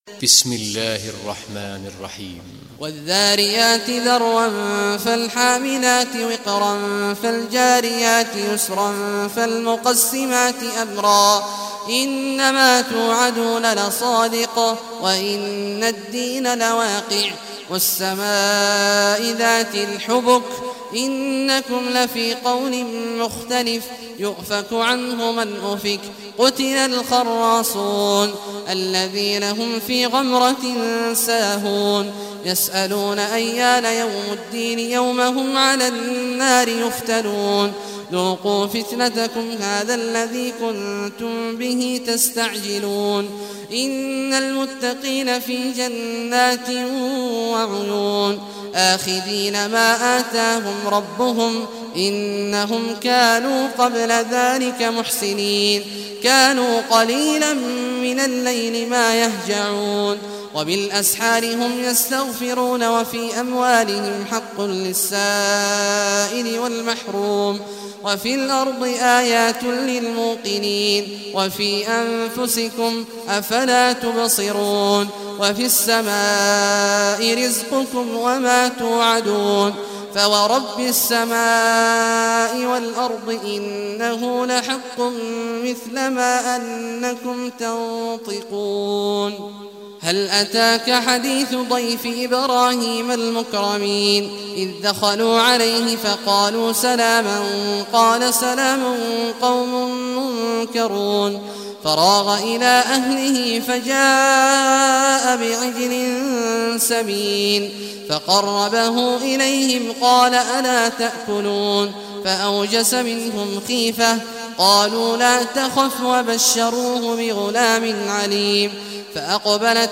Surah Dhariyat Recitation by Sheikh Awad Juhany
Surah Adh-Dhariyat, listen or play online mp3 tilawat / recitation in Arabic in the beautiful voice of Sheikh Abdullah Awad al Juhany.